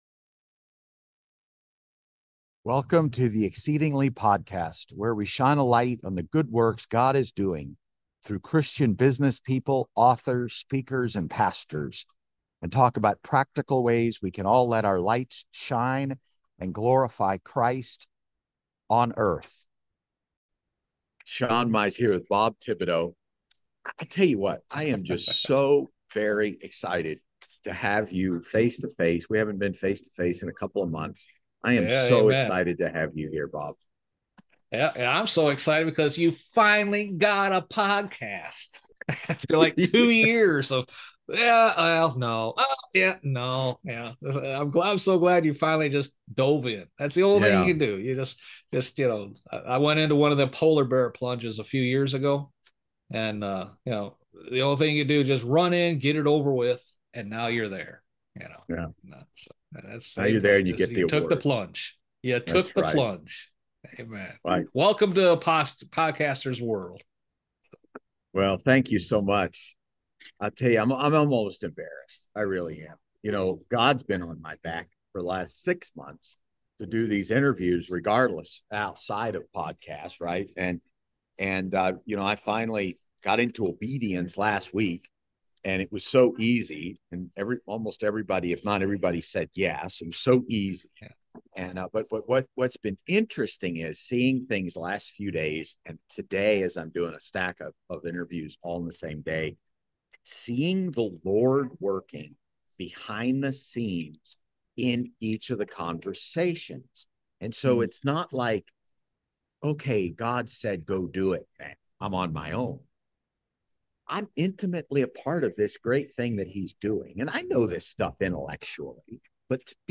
This podcast features an engaging conversation